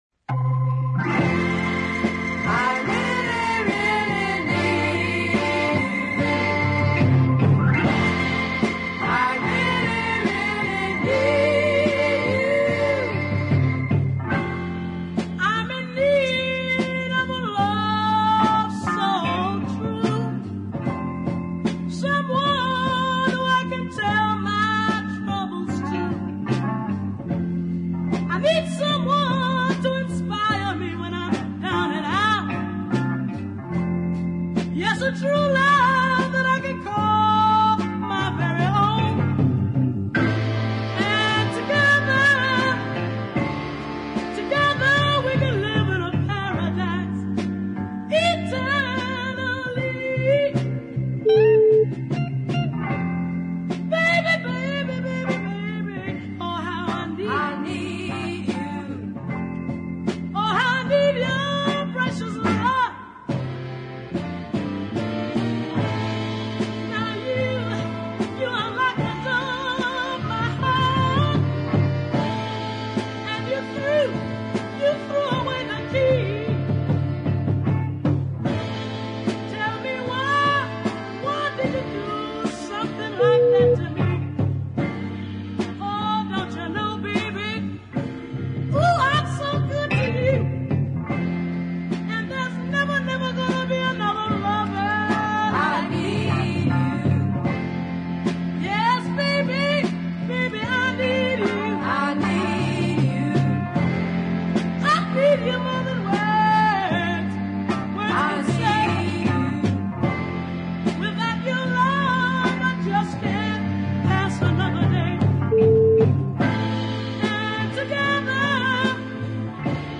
showed a clear delivery, and a fine sense of dynamics
is another tasty ballad.